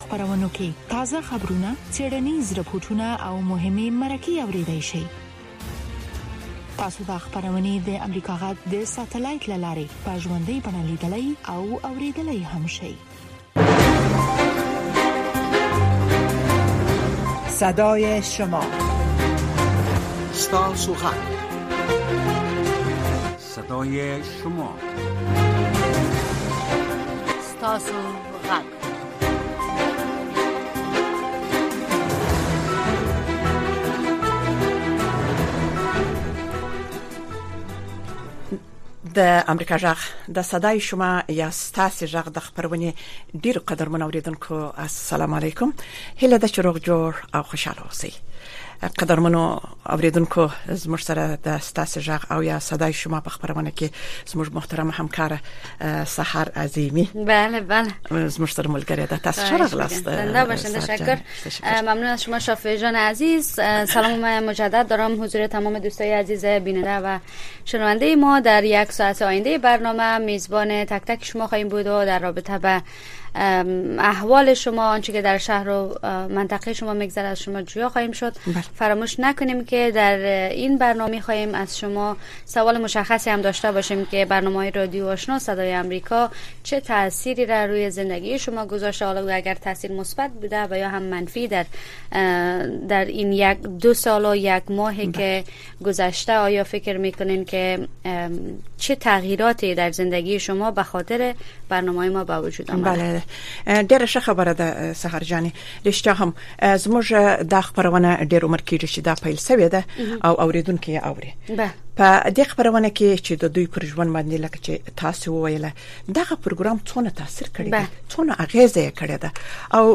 دا خپرونه په ژوندۍ بڼه د افغانستان په وخت د شپې د ۹:۳۰ تر ۱۰:۳۰ بجو پورې خپریږي.